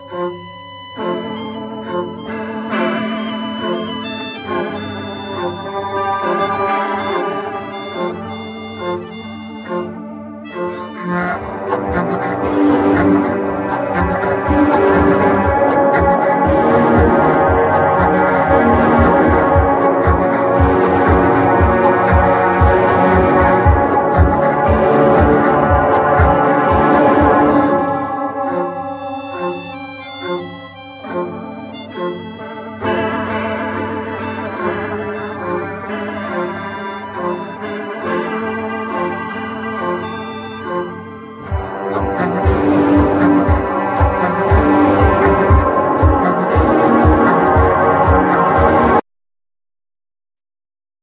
Ac.guitar,Organ,Piano,Bass,Melodica,Flute,Xylophne
Trumpet,Tronbone,Contrabass
Sanza,Steel-drum